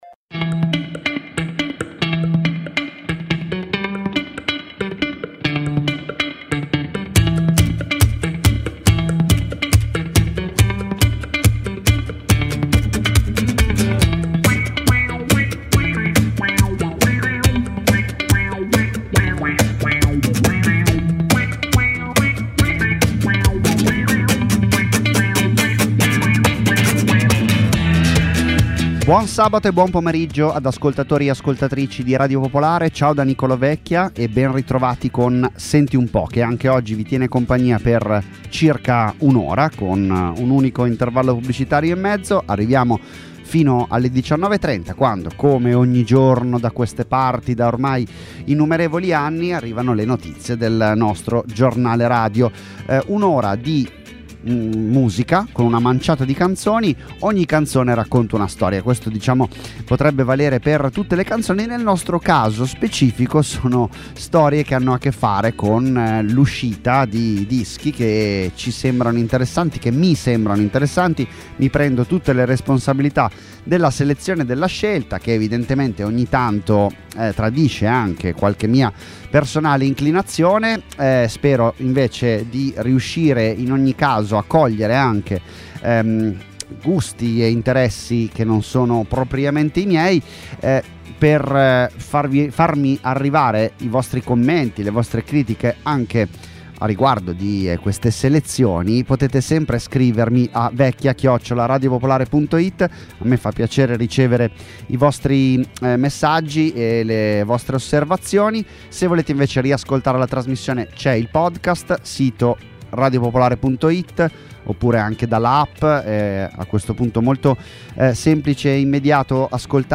Ospiti, interviste, minilive, ma anche tanta tanta musica nuova. 50 minuti (circa…) con cui orientarsi tra le ultime uscite italiane e internazionali.